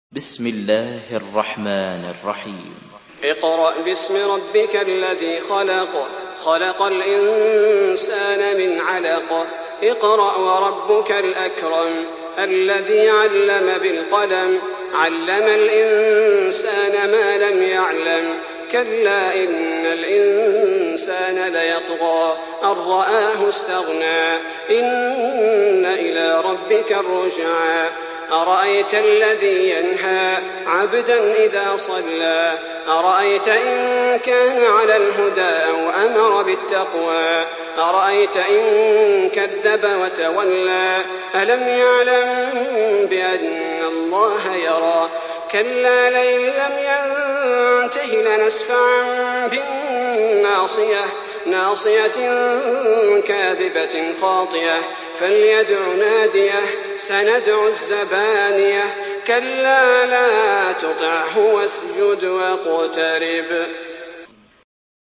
Surah Al-Alaq MP3 by Salah Al Budair in Hafs An Asim narration.
Murattal Hafs An Asim